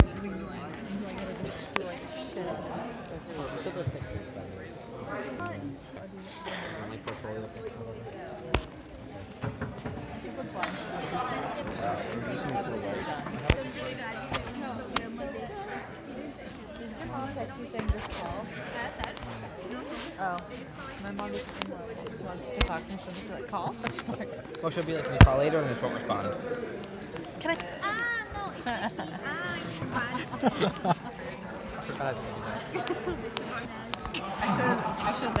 Location: Adams Playhouse Row E
Sounds Heard: Various conversations from the house before the show.
house-noise.mp3